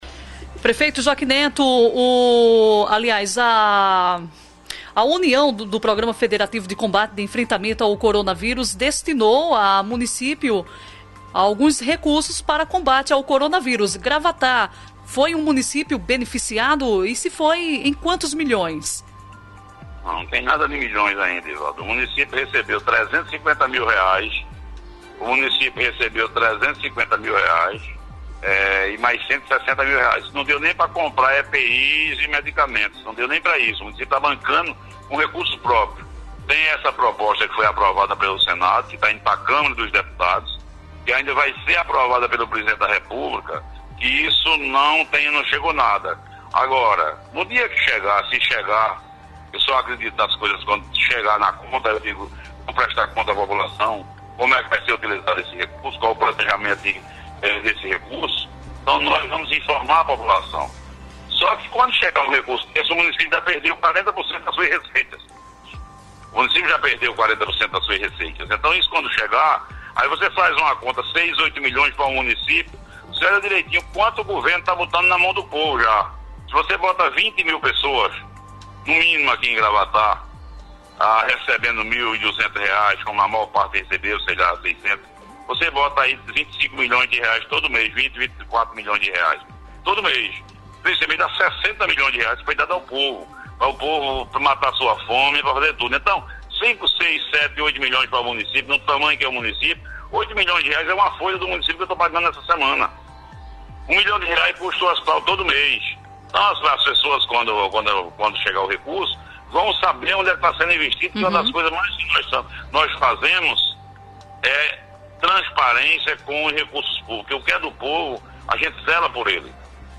Durante entrevista na Rádio Clima FM (98.5 MHz), o prefeito de Gravatá, Joaquim Neto (PSDB) revelou que Gravatá não recebeu nenhum recurso federativo carimbado para este tipo de enfrentamento.